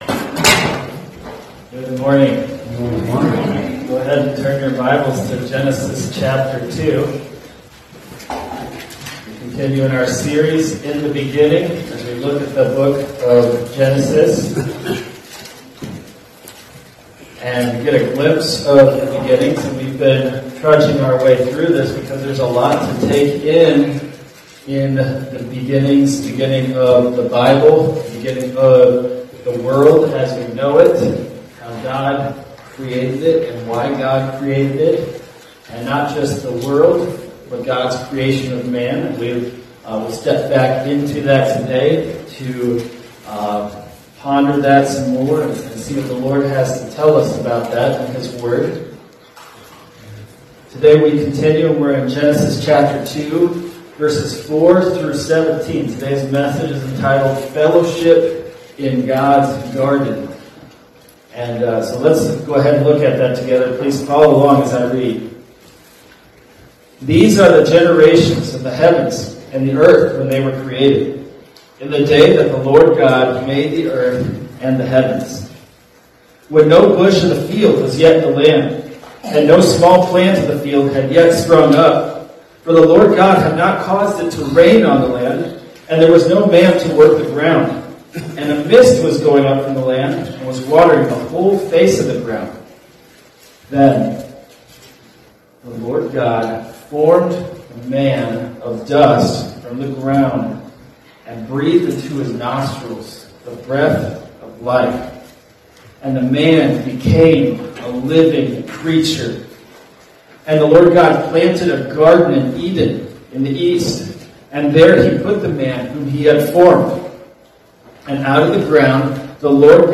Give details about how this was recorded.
Fellowship in God’s Garden – Grace Baptist Church